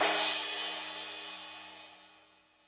Lazer Drums(27).wav